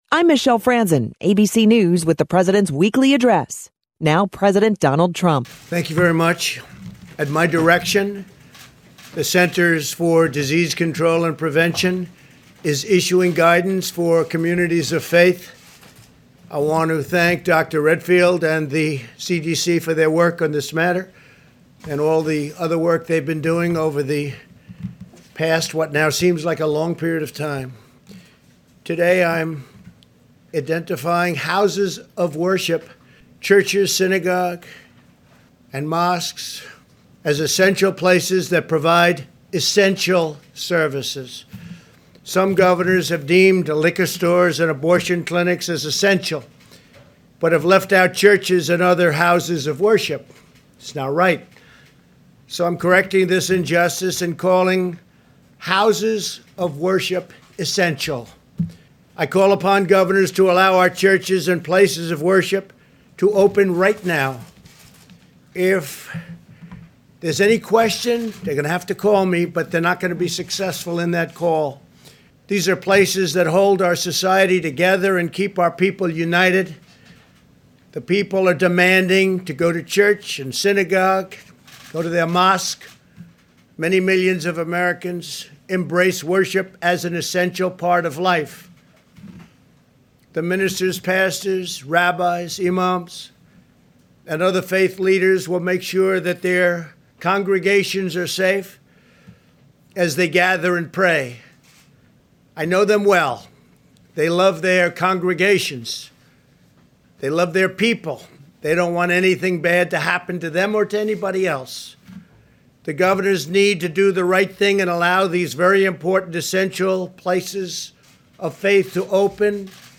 Trump was Monday’s KVML “Newsmaker of the Day”. Here are his words: